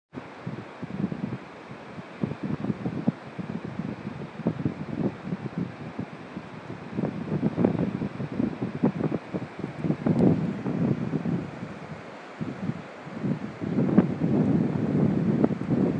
das meer :)